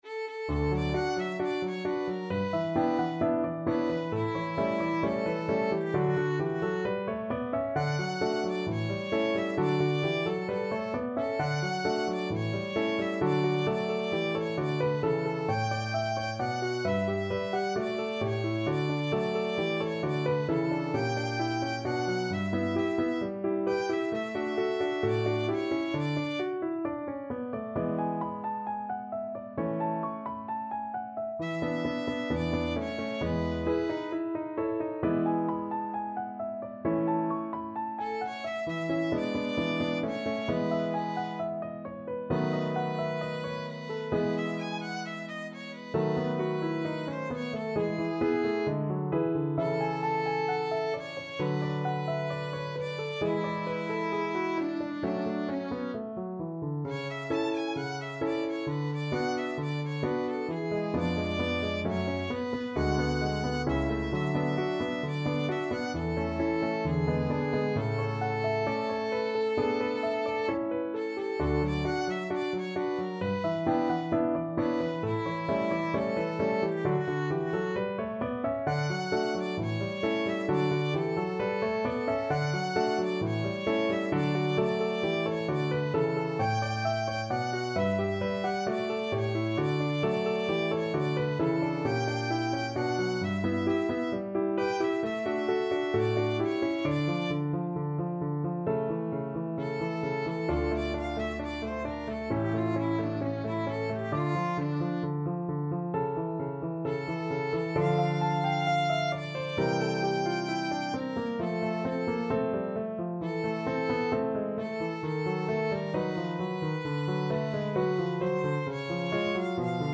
Classical Mozart, Wolfgang Amadeus Mi tradi quell'alma ingrata from Don Giovanni Violin version
D major (Sounding Pitch) (View more D major Music for Violin )
=132 Allegro assai (View more music marked Allegro)
2/2 (View more 2/2 Music)
Classical (View more Classical Violin Music)
mi_tradi_quell_alma_VLN.mp3